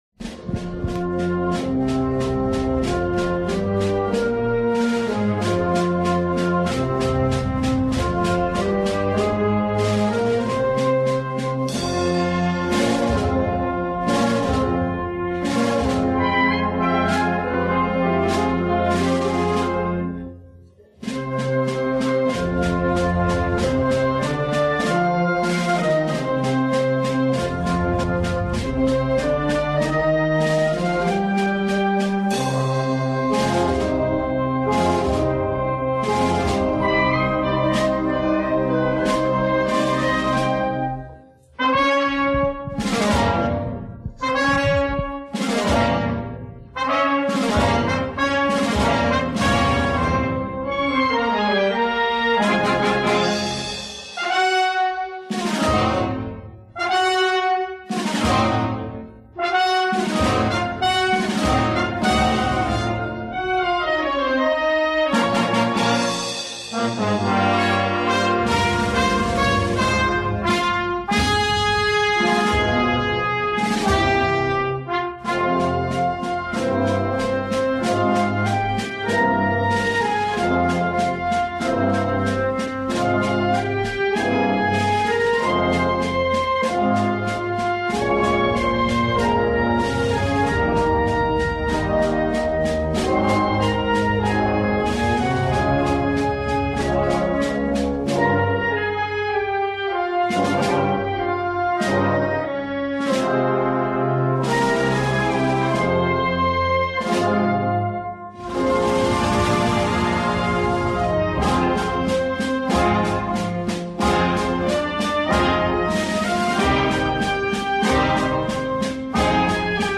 "Amarguras", himno de la Semana Santa
Es un auténtico poema sinfónico que a día de hoy (quién sabe si mañana) no se ha podido superar. La marcha comienza con unos primeros compases que tratan de evocar los rumores del cortejo procesional, destacan la omnipotencia de Cristo.
El segundo motivo es una frase de apacible dulzura, una conversación entre San Juan y la Virgen de la Amargura que llegará a su más alto grado de sonoridad. El tercer motivo (en forma de coral) en "pianísimo" traza los rezos de los creyentes interrumpiéndose con el sonido de las trompetas romanas.
Se esboza el comienzo de una saeta pero se interrumpe por campanas culminando, en "fortísimo" esta composición.
descargar una versión de la marcha "Amarguras" ejecutada por la Banda Municipal de Sevilla en el pregón de su Semana Santa de 2000.